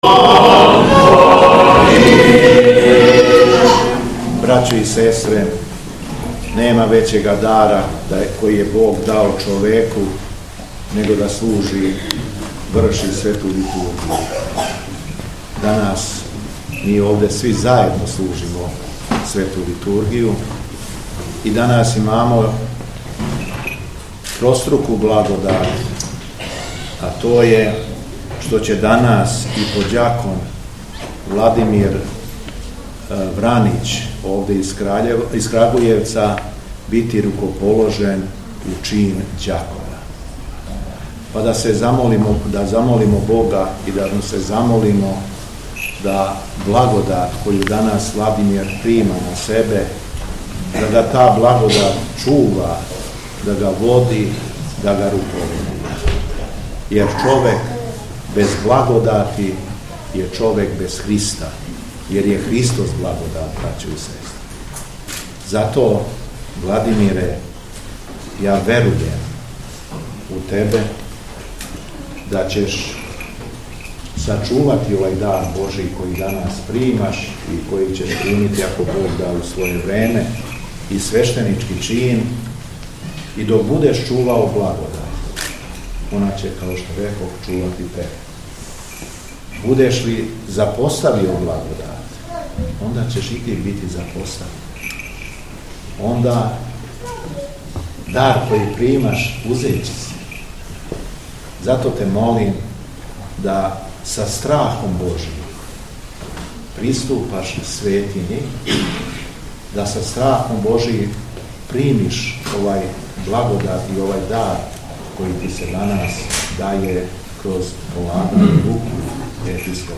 НЕДЕЉА ПРОТАЦА - МАТЕРИЦЕ У СТАРОЈ ЦРКВИ У КРАГУЈЕВЦУ
Беседећи верном народу Митрополит Јован је рекао:
Беседа Његовог Високопреосвештенства Митрополита шумадијског г. Јована